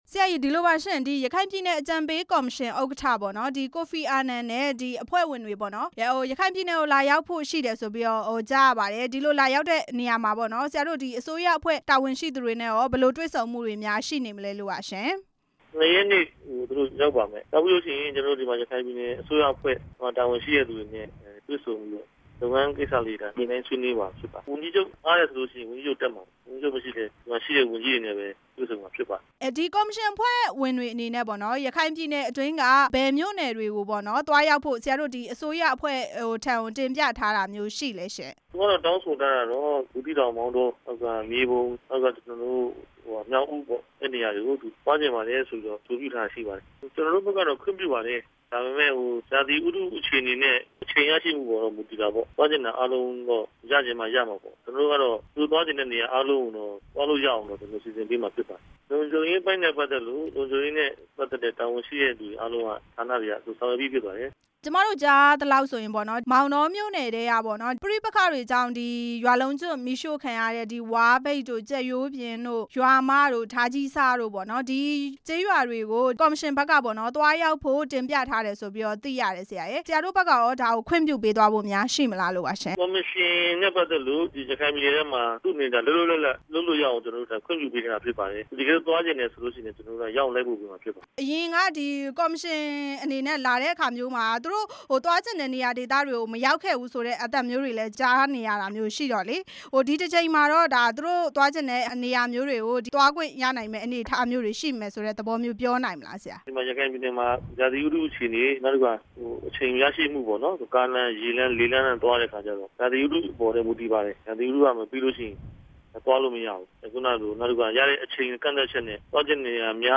ဆက်သွယ် မေးမြန်းထားပါတယ်။